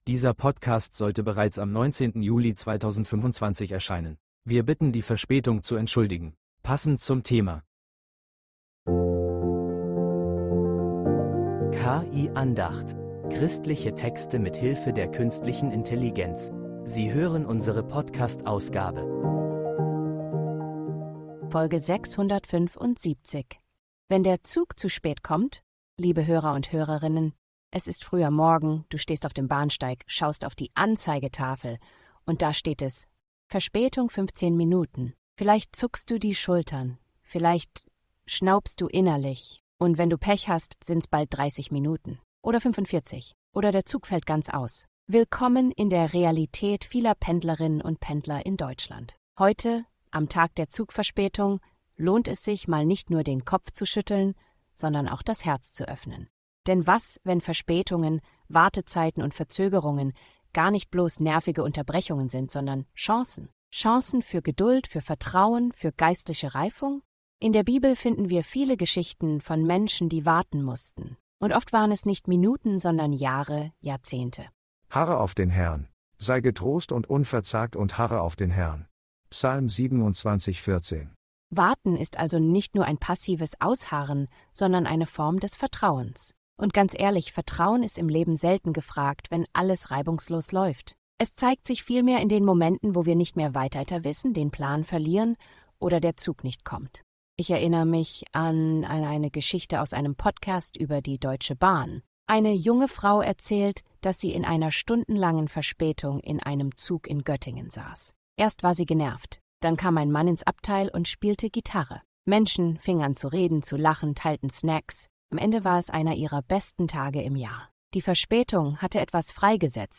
Eine Andacht über Geduld, Hoffnung und ungeplante Zwischenhalte